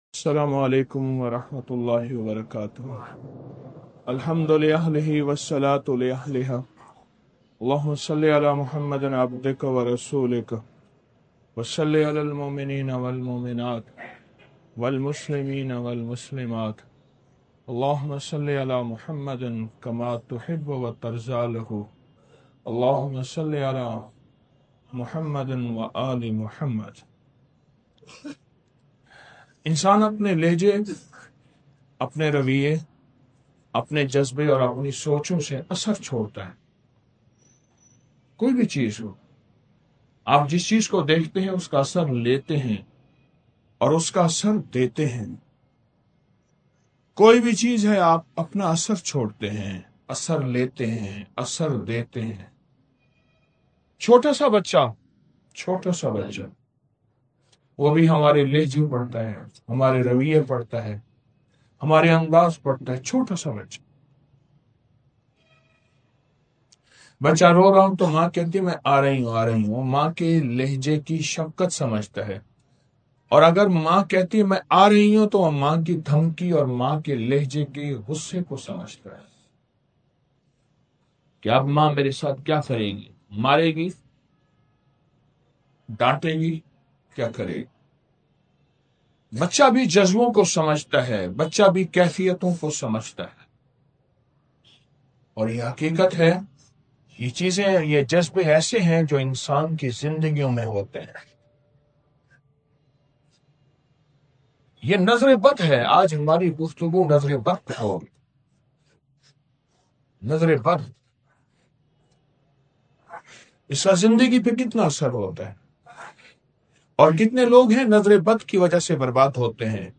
09 سوا کروڑ سورۃ الاخلاص | 06 نومبر 2025 | نَظَرِ بَد کا وار | شبِ جمعہ محفل